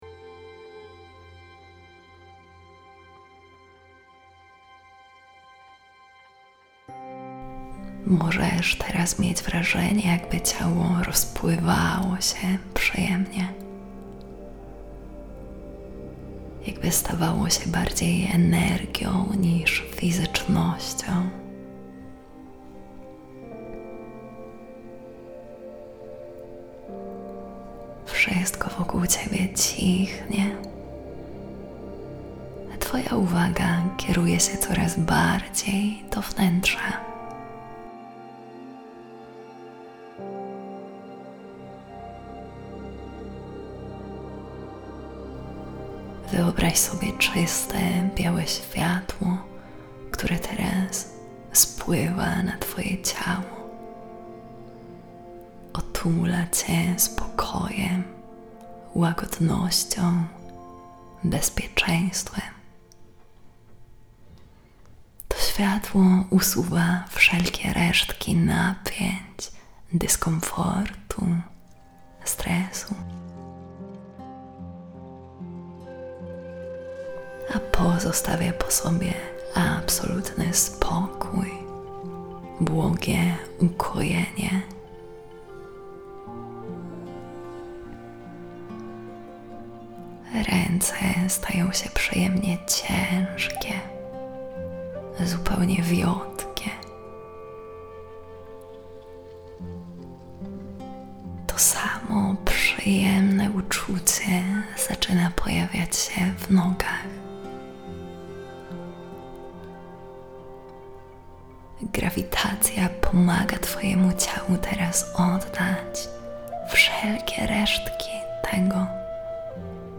Autohipnoza głębokiej samoakceptacji i odwagi bycia sobą